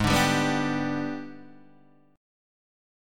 AbmM7b5 chord